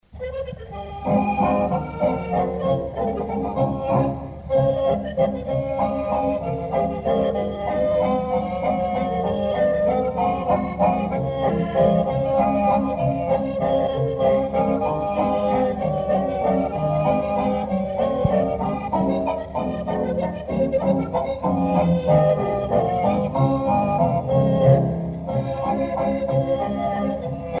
danse : java
Pièce musicale éditée